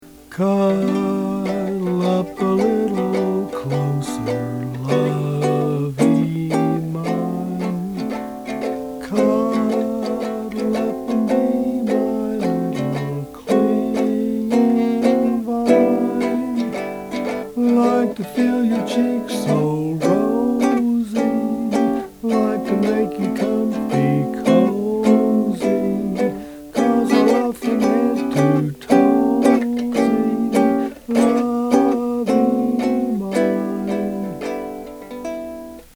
Ukulele mp3 songs from sheet music
Please ignore any sour notes.